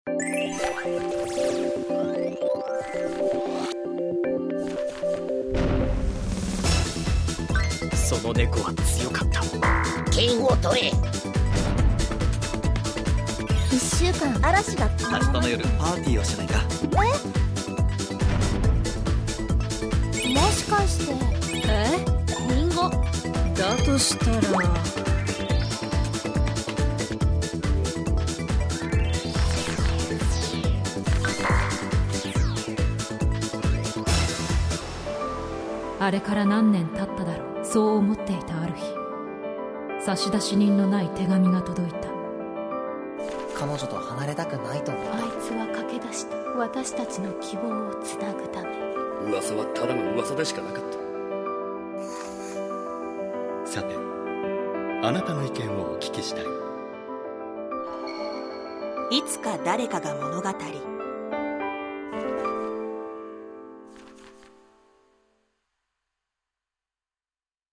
[オーディオブック] イツカダレカガ物語
この作品は朗読に近いものから会話劇まで様々。
物語を朗読、音の小説を読んでいただくイメージで楽しんでください。